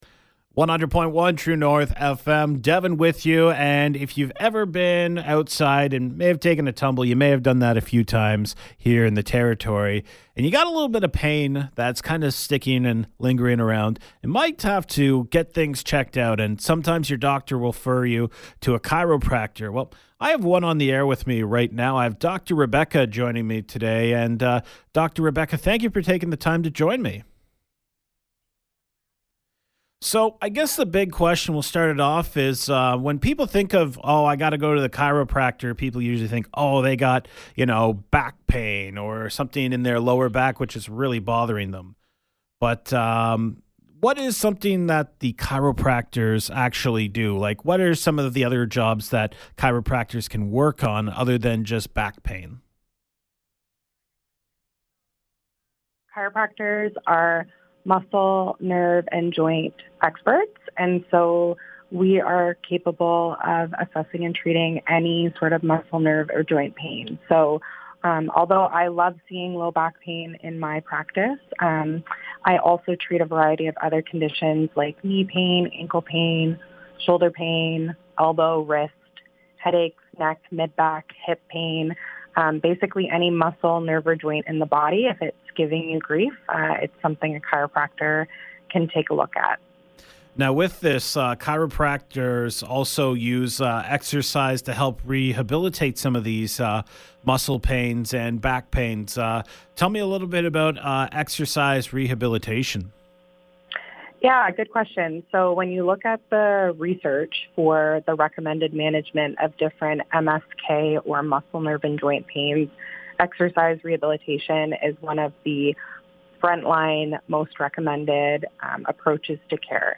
100.1 True North FM Yellowknife Radio Interview